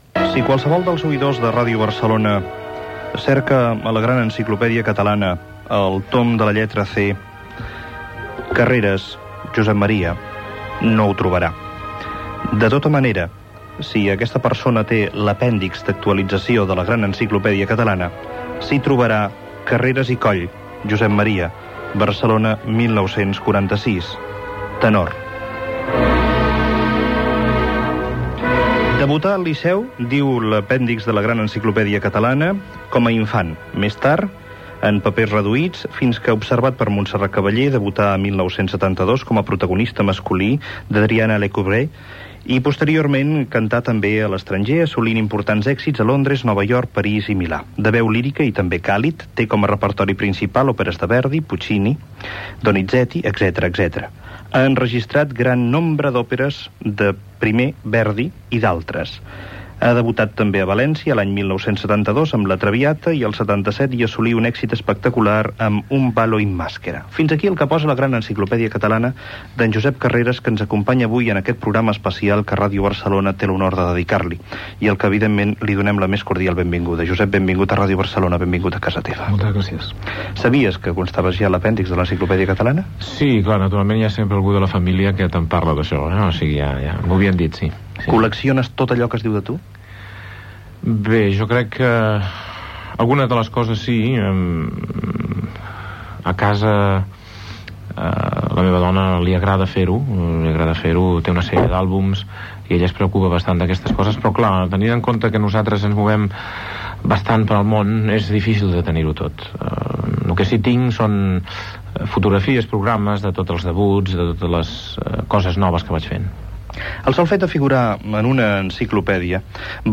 Cultura
Cuní, Josep